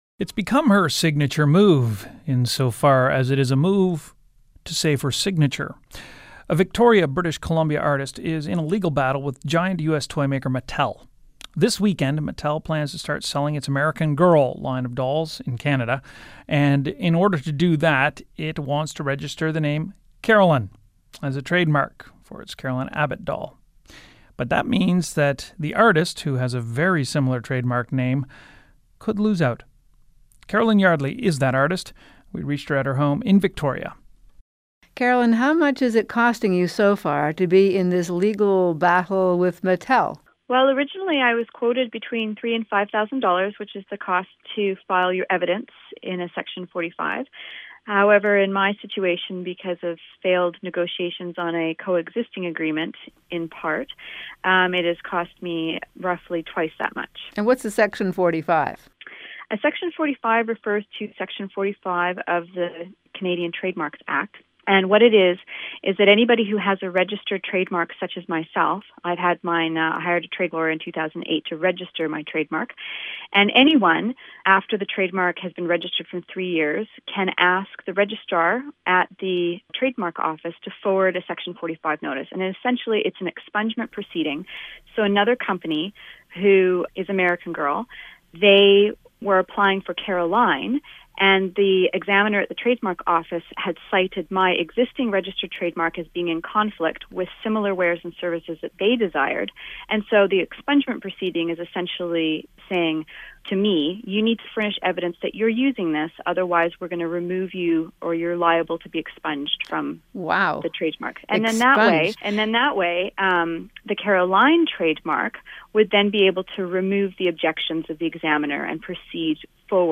CBC Radio, As It Happens, Carol Off (Radio Listen Now 5:25)